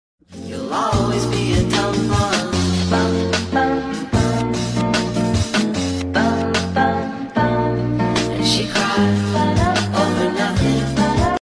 Versión saxofonista